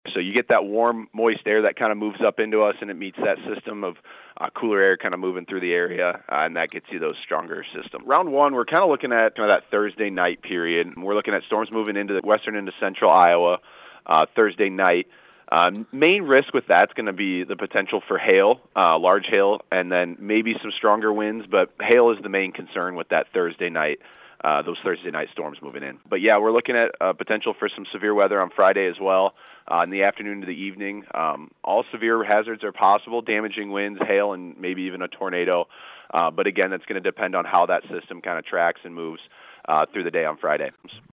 National Weather Service Meteorologist